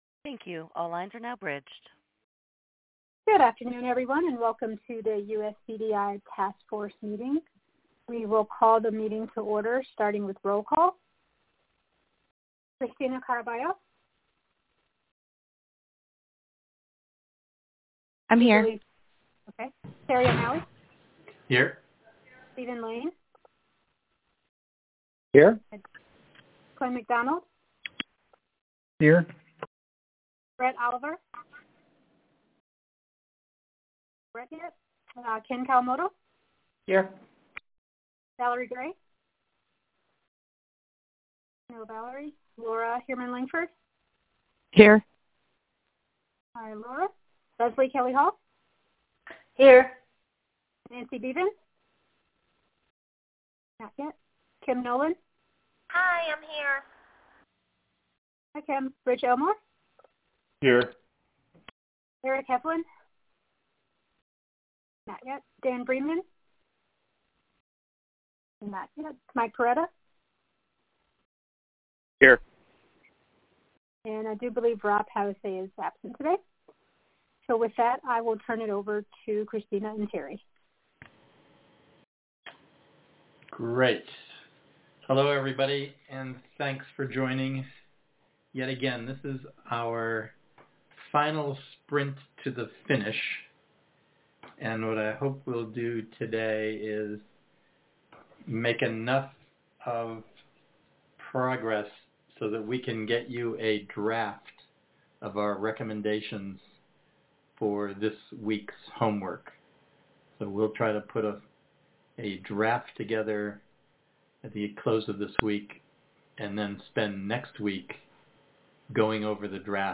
U.S. Core Data for Interoperability (USCDI) Task Force Meeting Audio 4-4-2018